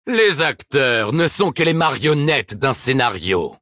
A bit deeper voice compared to his English performance, and even more so his JP one. He feels more menacing in a way.
Also, the contrast between the select lines is pretty funny, real in and out of character talking.